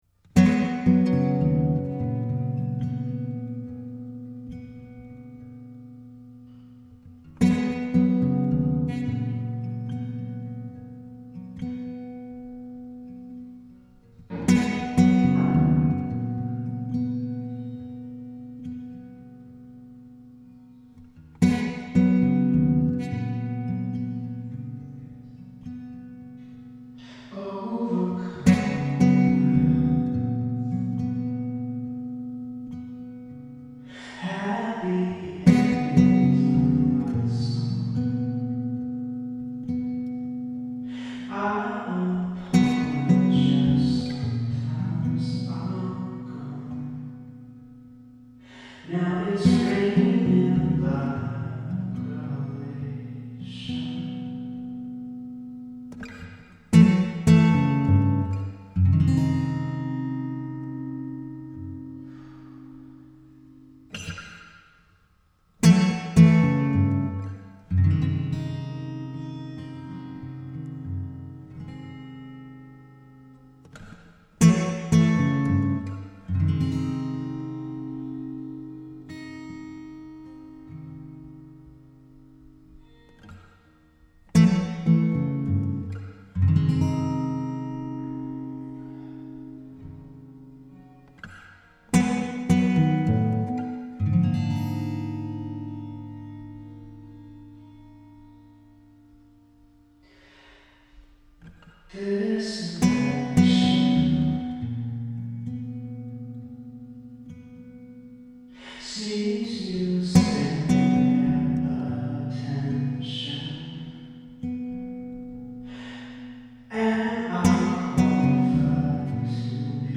Folk
World music